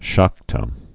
(shäktə, säk-)